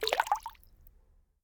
water-splash-04
bath bathroom bubble burp click drain dribble drop sound effect free sound royalty free Nature